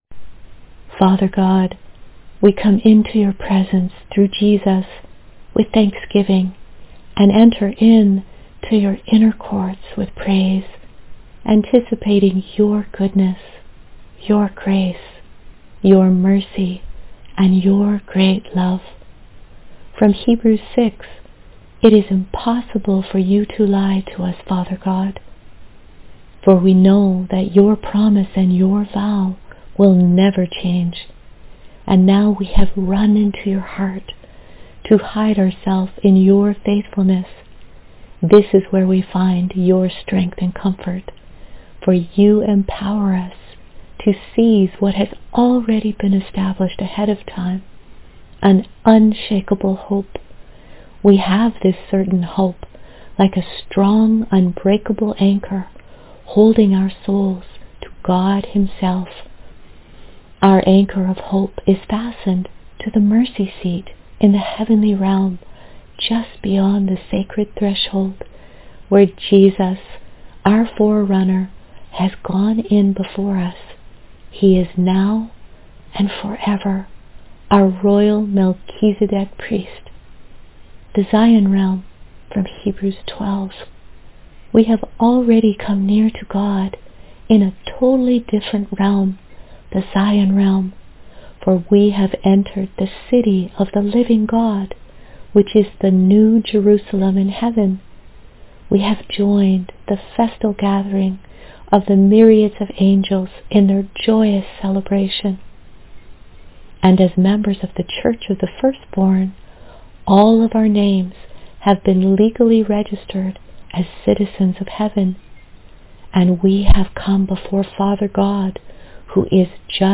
The Throne of Grace — a Hebrews medley — audio prayer and verse